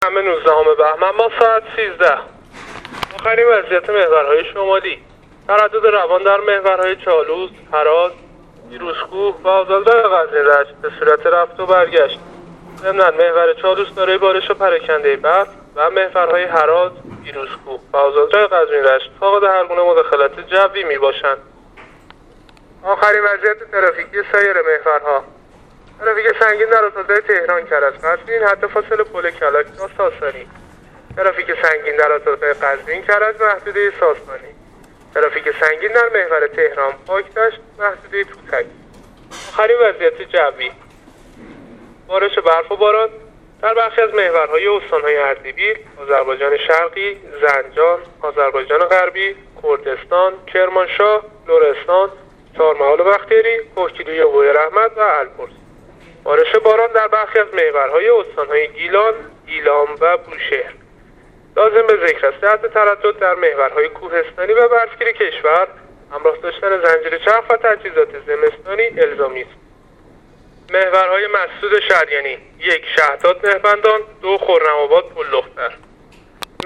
گزارش رادیو اینترنتی از آخرین وضعیت ترافیکی جاده‌ها تا ساعت ۱۳ شنبه ۱۹ بهمن‌ماه ۱۳۹۸